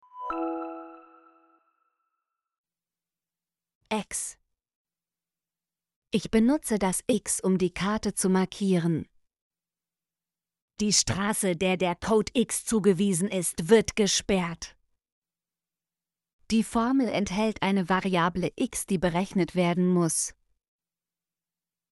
x - Example Sentences & Pronunciation, German Frequency List